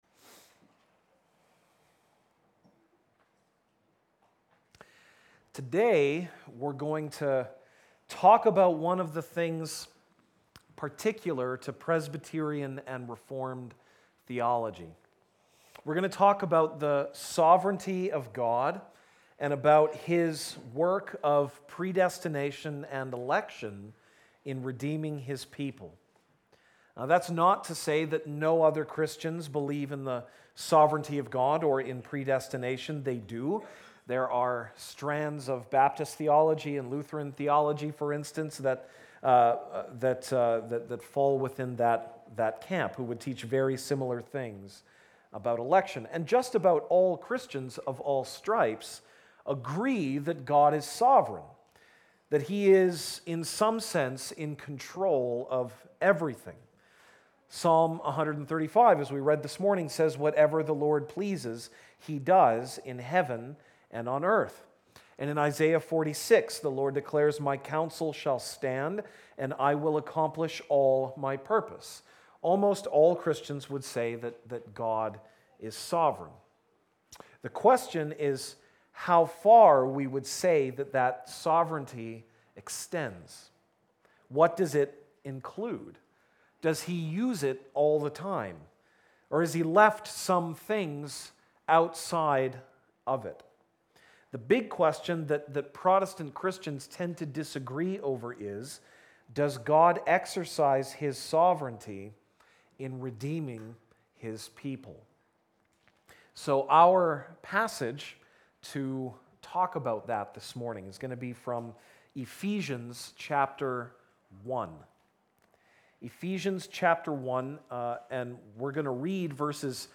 January 13, 2019 (Sunday Morning)